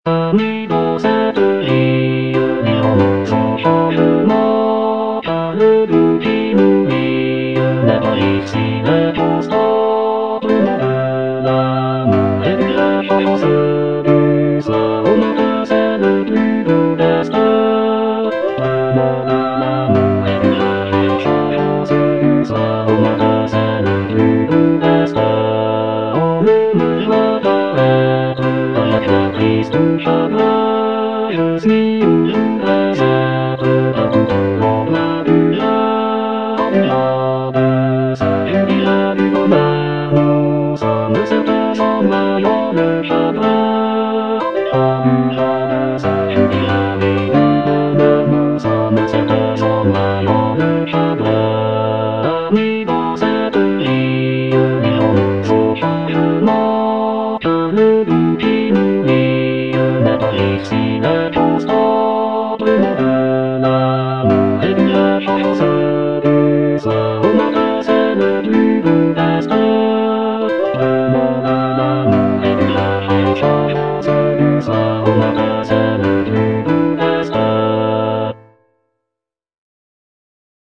Bass (Emphasised voice and other voices)
traditional French folk song